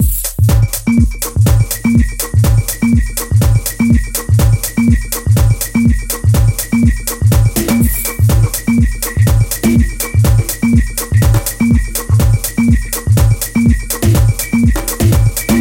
描述：鼓环
标签： 123 bpm Electronic Loops Drum Loops 3.94 MB wav Key : Unknown
声道立体声